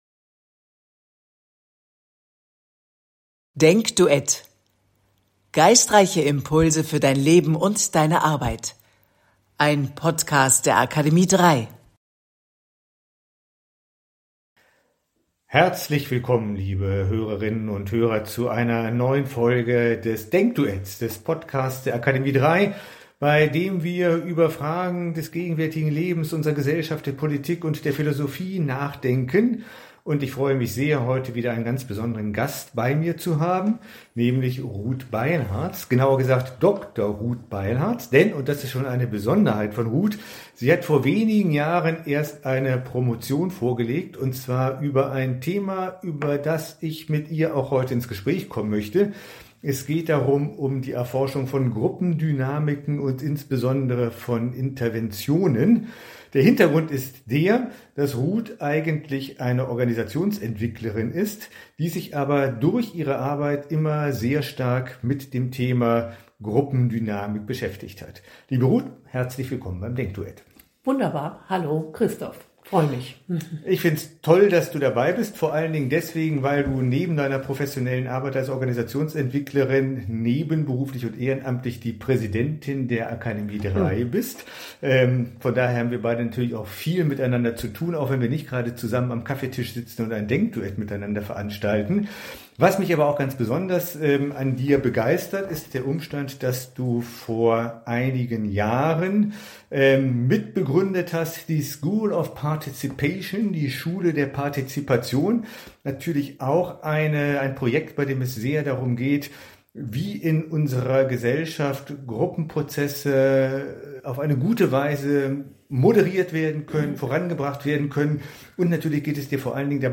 DenkDuett - ein freies Spiel der Gedanken; nicht vorgefertigt und abgerufen, sondern frisch und freihändig im Augenblick entwickelt.